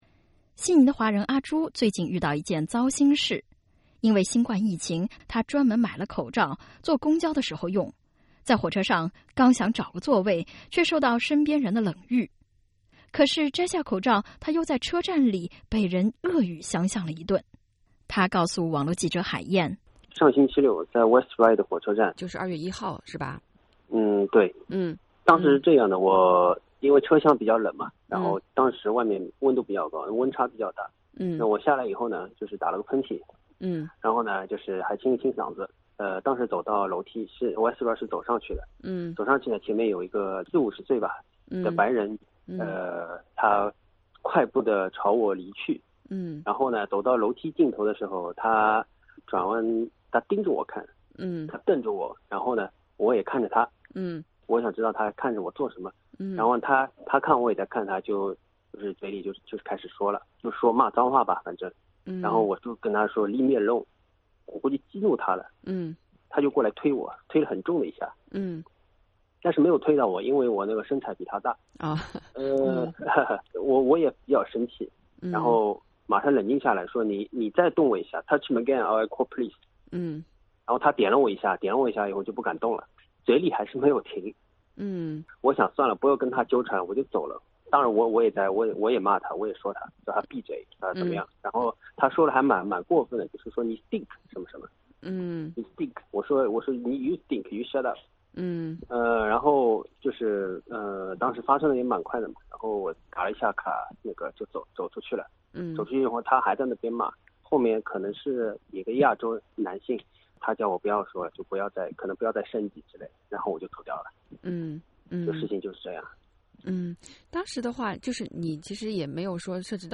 mask_phobia_vox_pop.mp3